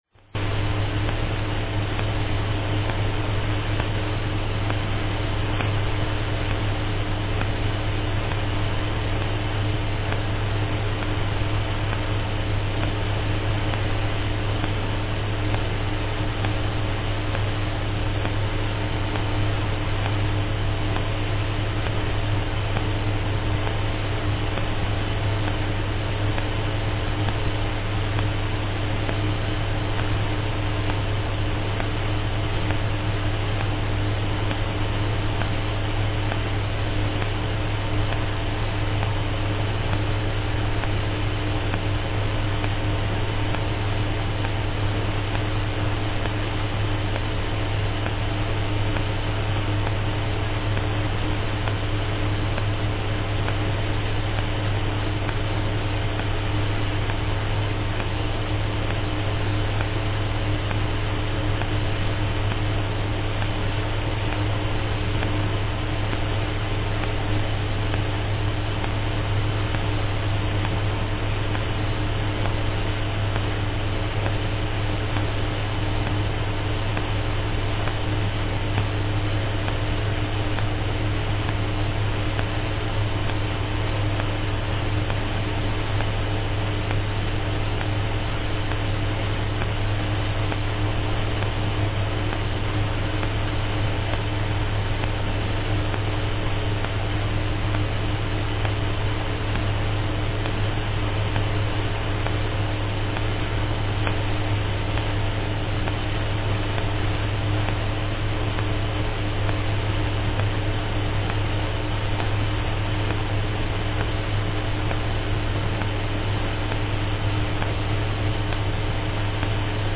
Here is the actual audio for that nearly five-minute period during which there were no transmissions.
1007-Five-Minutes-no-transmission.mp3